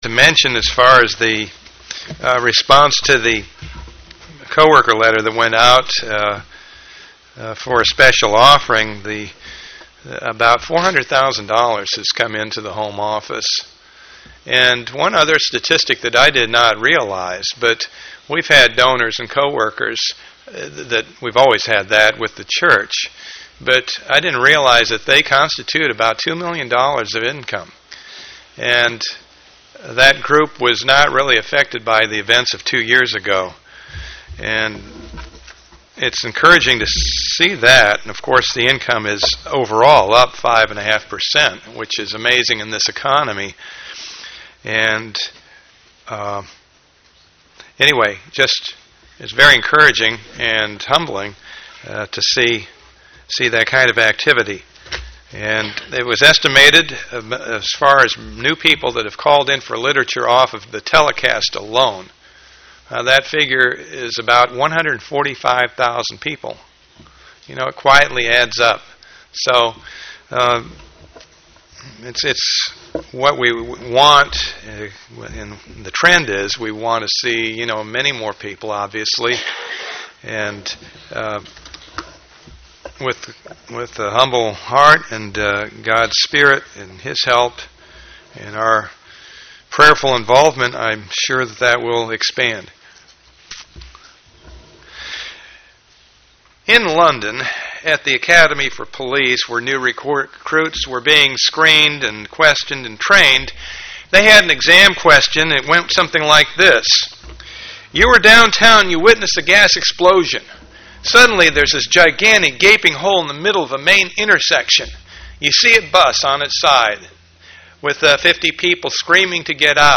Given in St. Petersburg, FL
UCG Sermon Studying the bible?